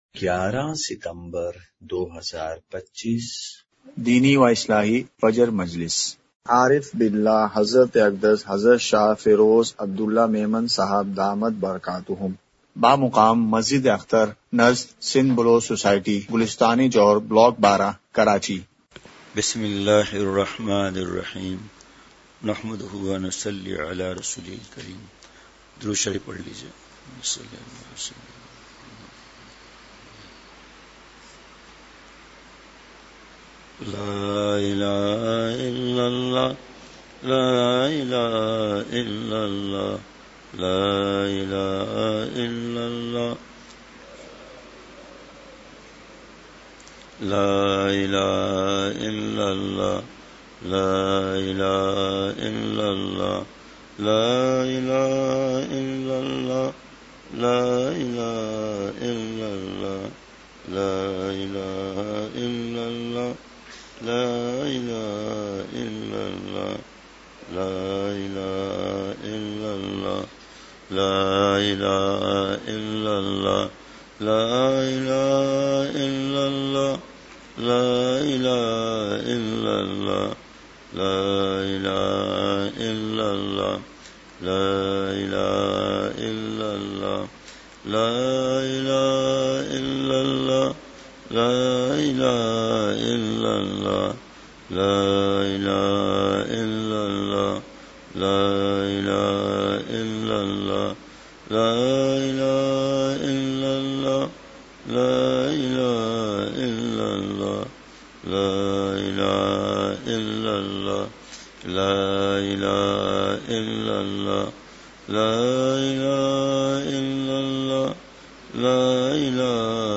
*مقام:مسجد اختر نزد سندھ بلوچ سوسائٹی گلستانِ جوہر کراچی*
مجلسِ ذکر:کلمہ طیّبہ۔۔۔!!